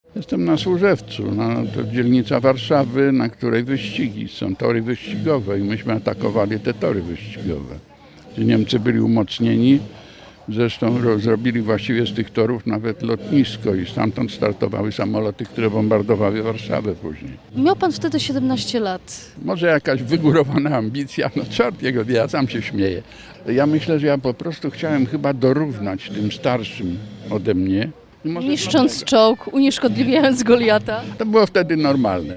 Obchody w Olsztynie